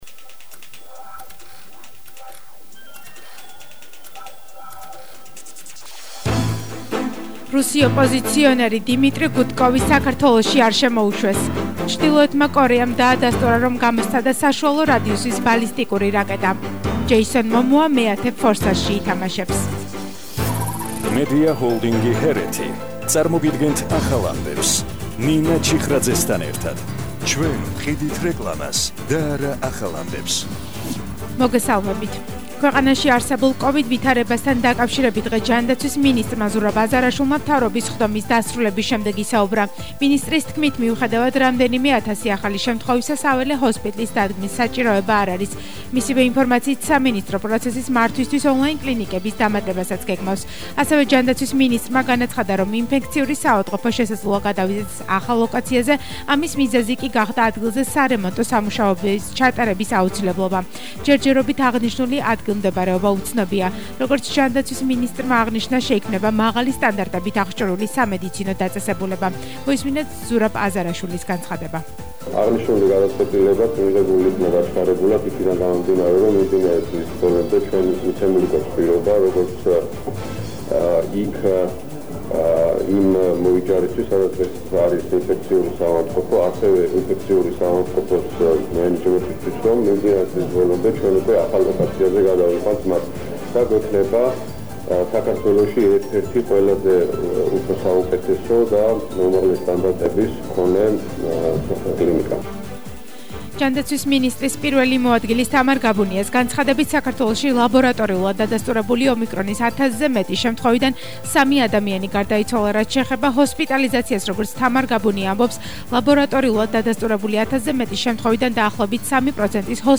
ახალი ამბები 17:00 საათზე – 31/01/22 - HeretiFM